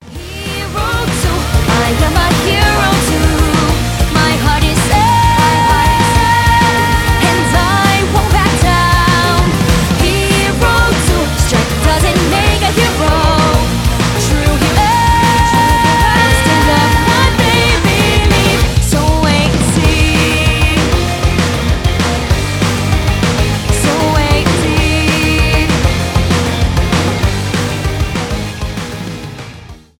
power pop , pop rock
j-rock